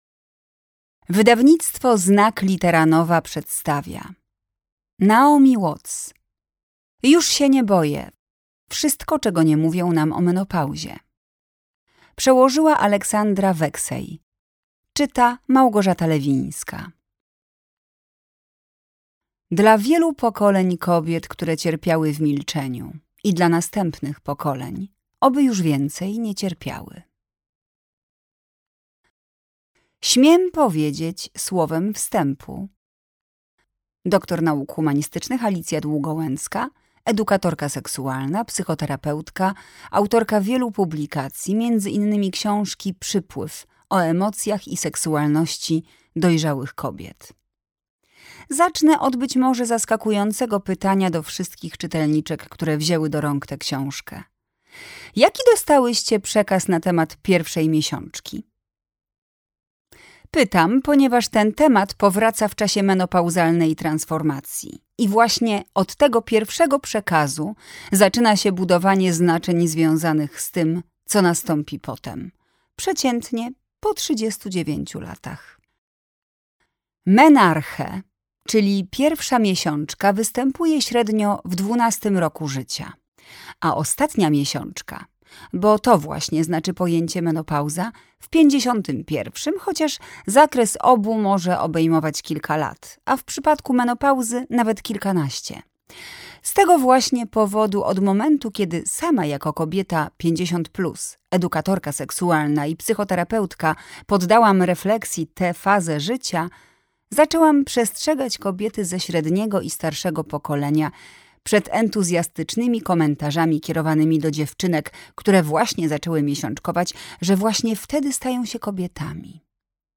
Już się nie boję! Wszystko, czego nie mówią nam o menopauzie - Watts Naomi - audiobook